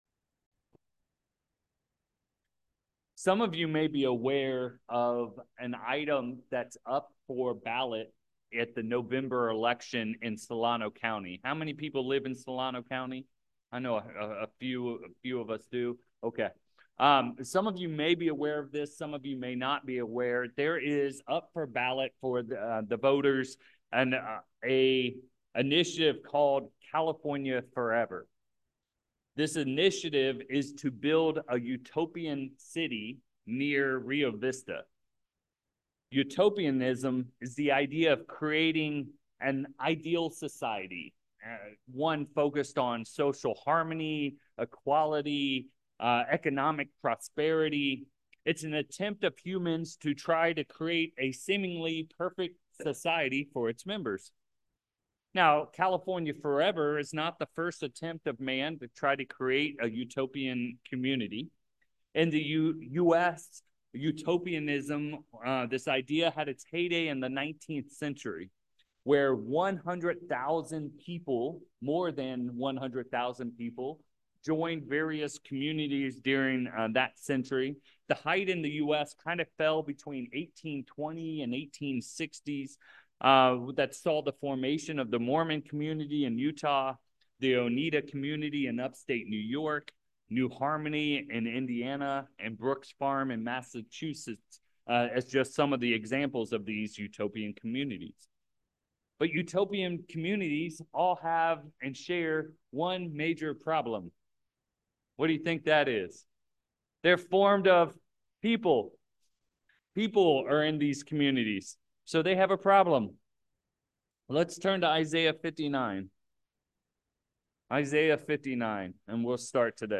In this sermon, we dive into this important Fruit of the Spirit.